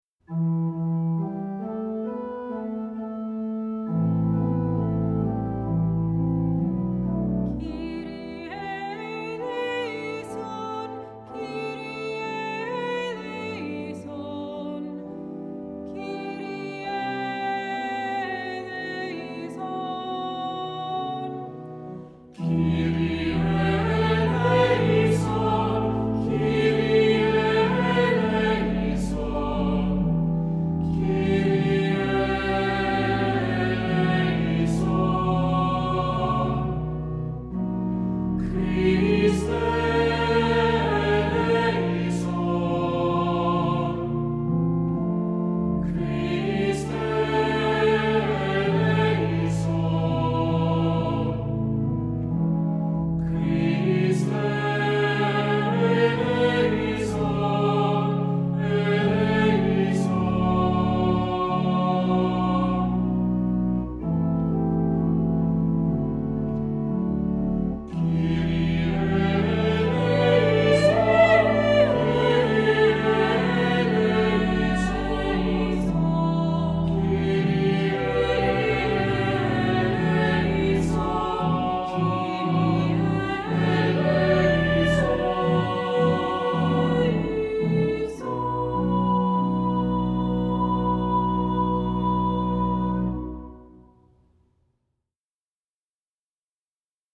Voicing: Unison with descant; Assembly